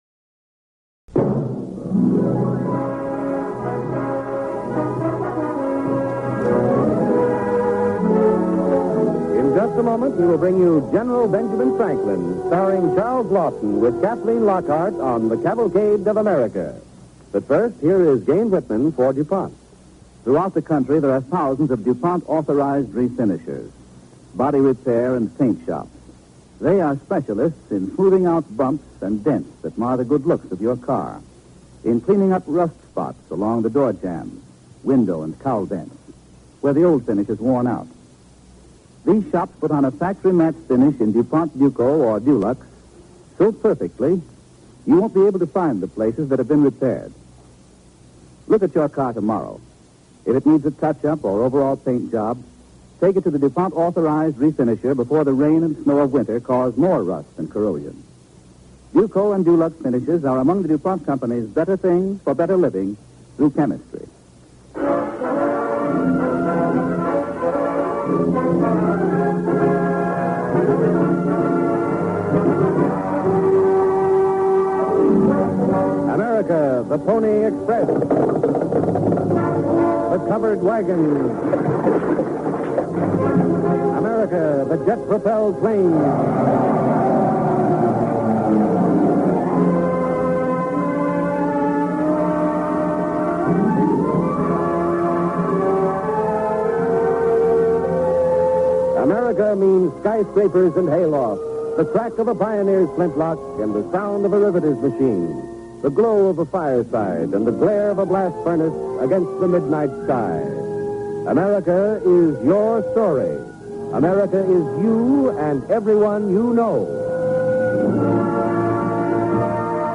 Cavalcade of America Radio Program
General Benjamin Franklin, starring Charles Laughton and Howard Duff